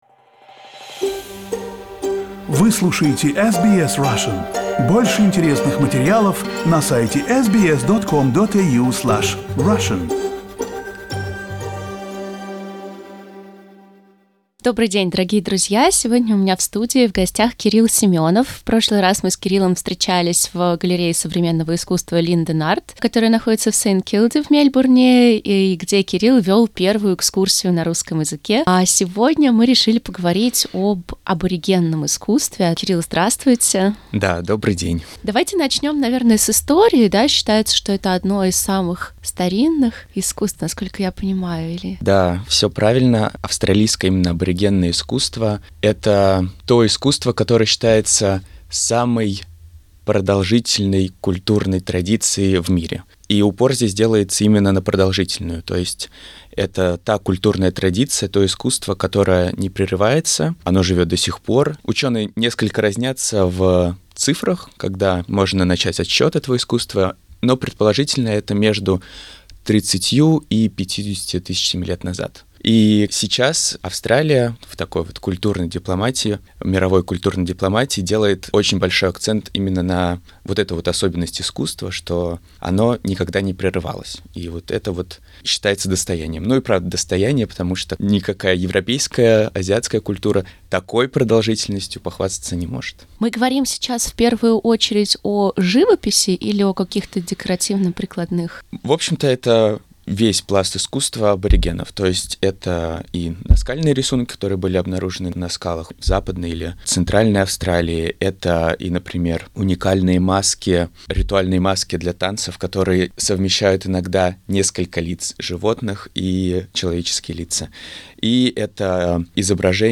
слушайте в интервью